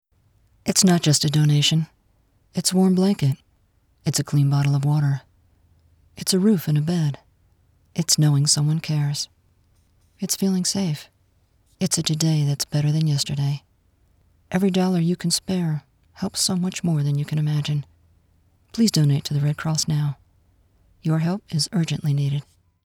Female
My voice is neutral American English - best described as low, rich, smooth, elegant, sultry, intelligent, smokey, confident and sophisticated - well suited for luxury & automotive, commercial, high tech and documentary.
Natural Speak
Natural, Vulnerable, Honest